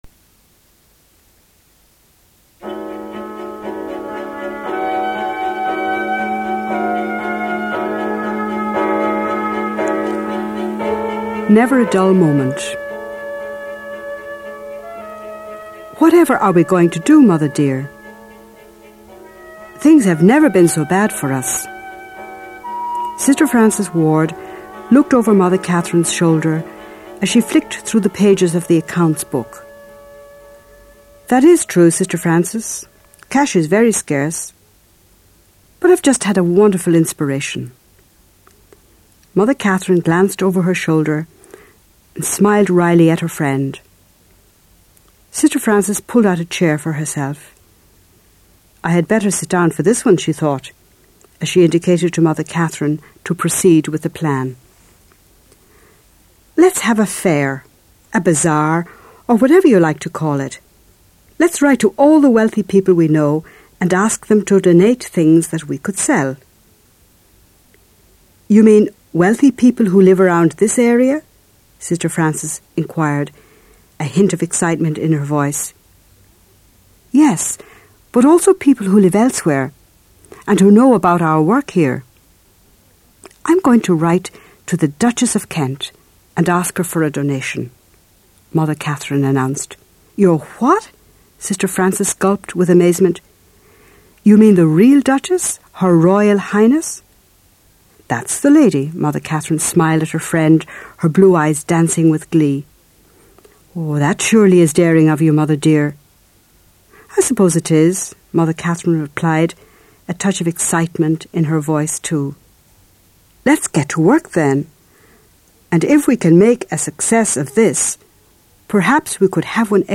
The story of Catherine McAuley for younger listeners